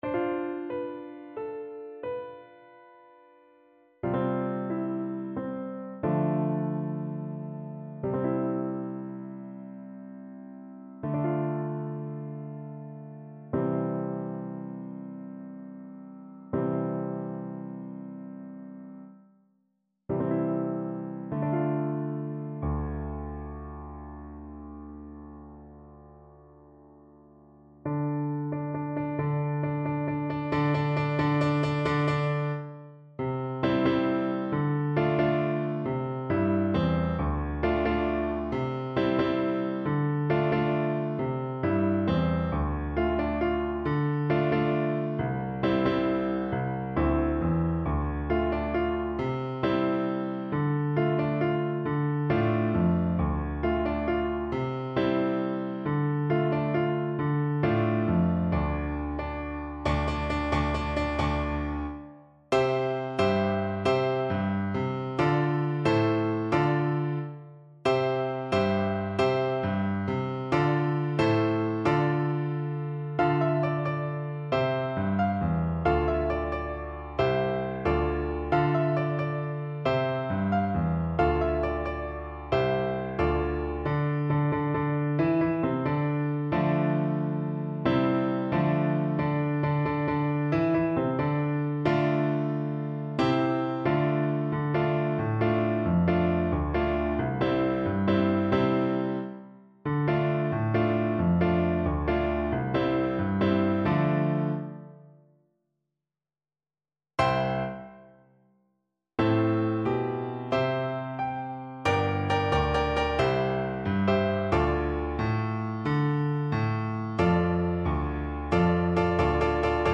Flute
D minor (Sounding Pitch) (View more D minor Music for Flute )
Slow .=c.80
Traditional (View more Traditional Flute Music)